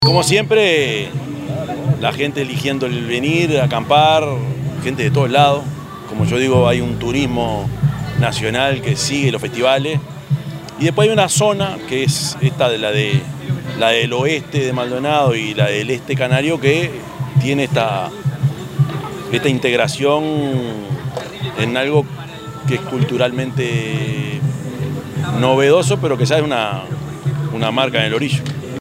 yamandu_orsi_intendente_de_canelones_9.mp3